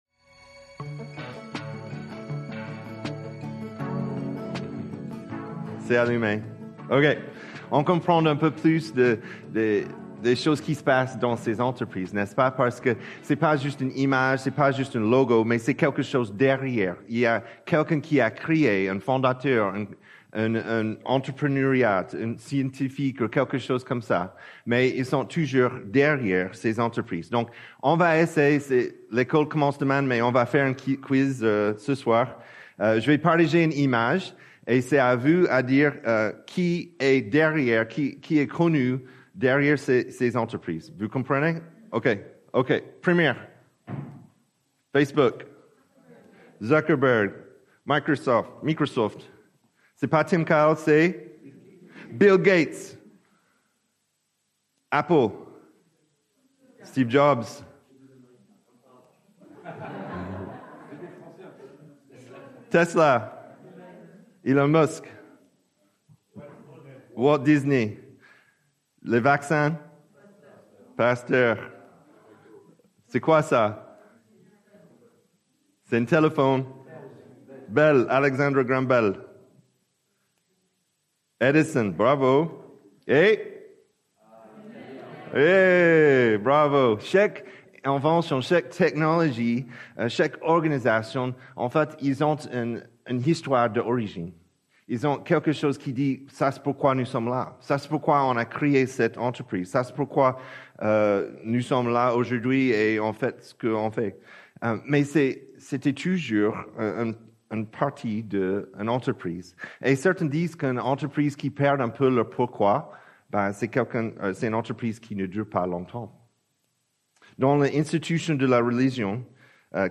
Predication1-5.mp3